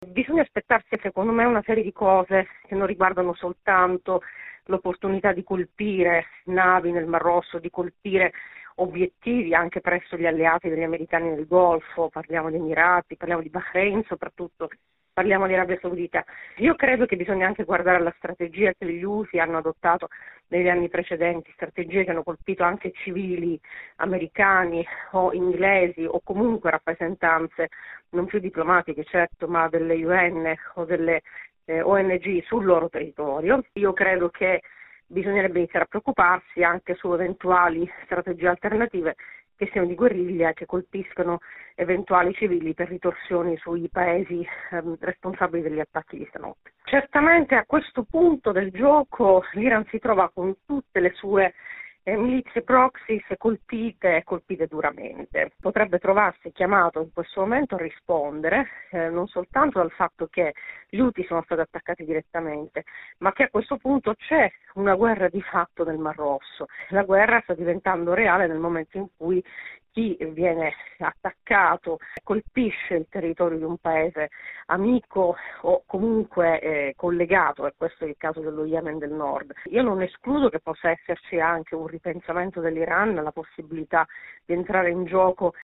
giornalista esperta di Yemen